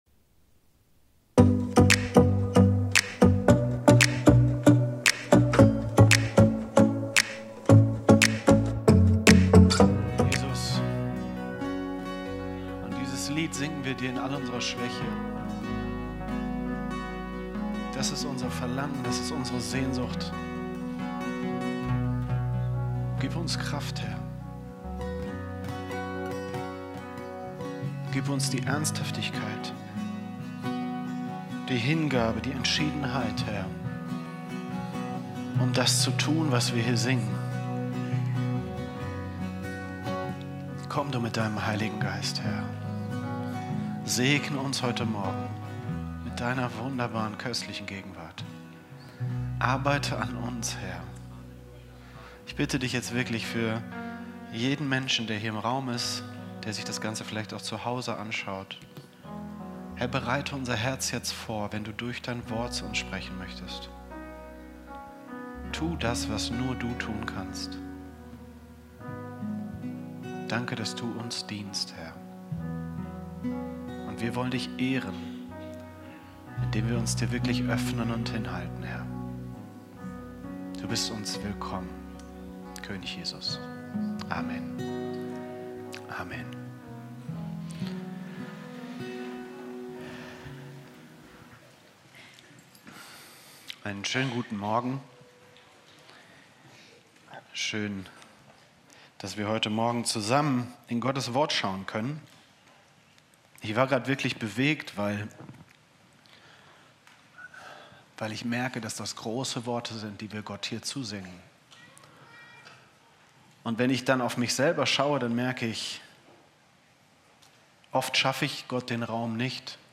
Live-Gottesdienst aus der Life Kirche Langenfeld.
Kategorie: Sonntaggottesdienst Predigtserie: Church on fire